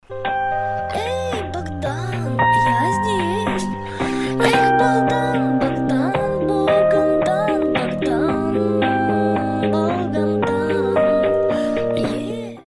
Клубные рингтоны
Electro house , Техно , EDM